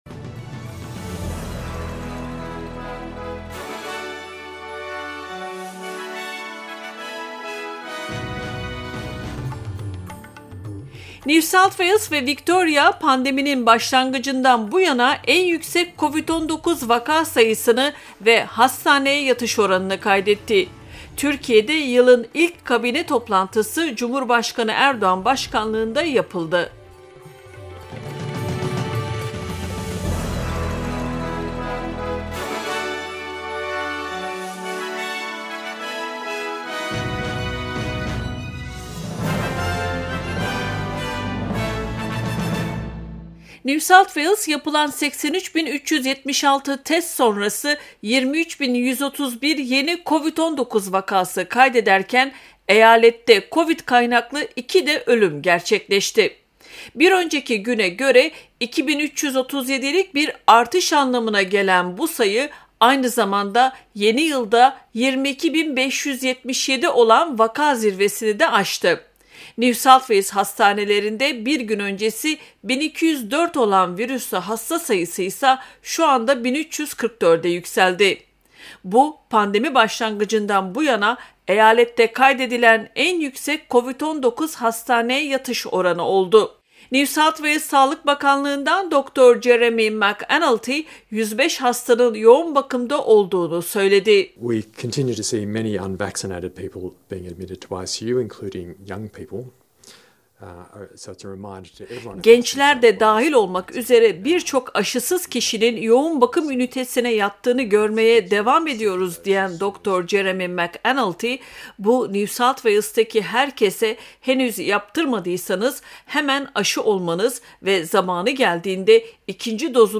news_bulletin_4_jan_2022.mp3